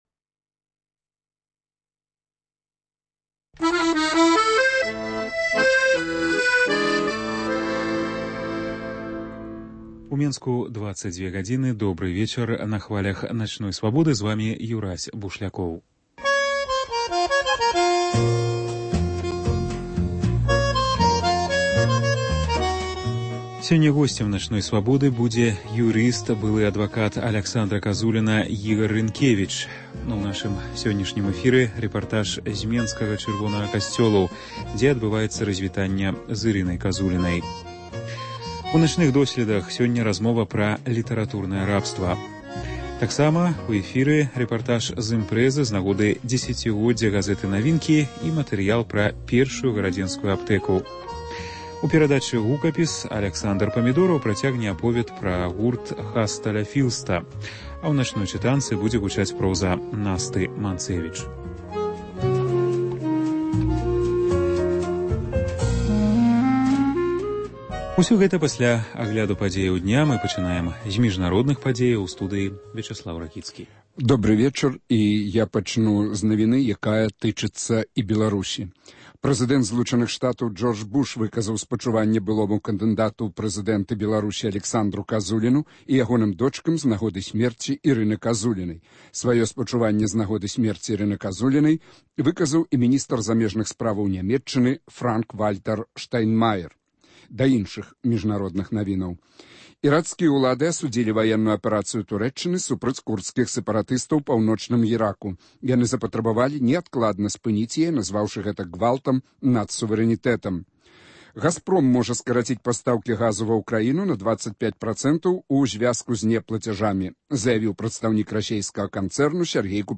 Госьць: юрыст
Жывы рэпартаж зь менскага Чырвонага касьцёлу